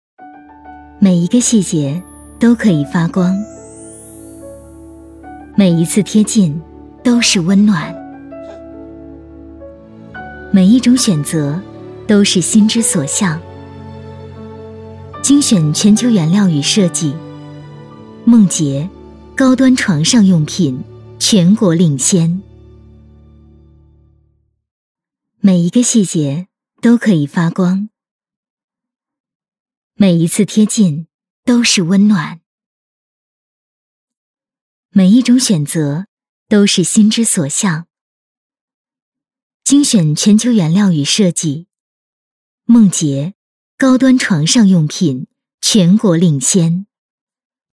女218-广告 【亲和】梦洁家纺
女218-温柔甜美 温柔知性
女218-广告 【亲和】梦洁家纺.mp3